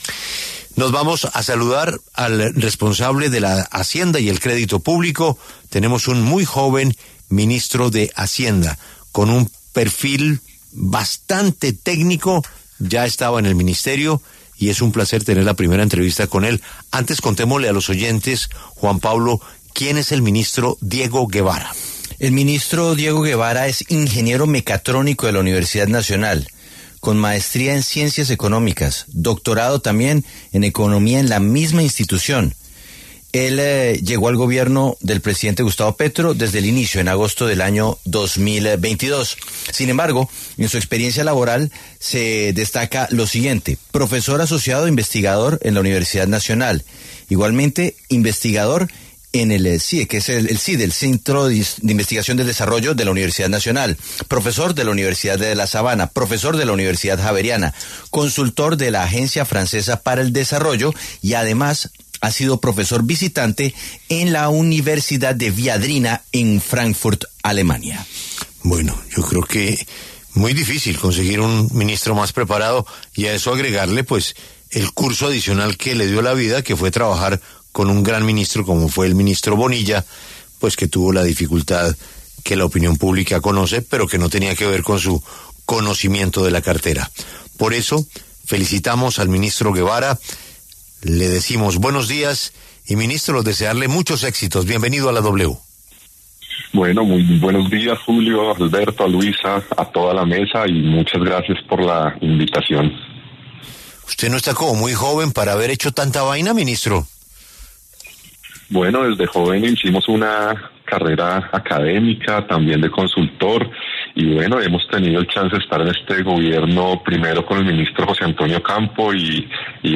El ministro de Hacienda, Diego Guevara, habló en W Radio sobre la reforma tributaria, con la que esperan recaudar $ 9.2 billones, y aseguró que en caso de que se hunda harán un recorte en el Presupuesto de 2025 para mandar un mensaje de responsabilidad fiscal.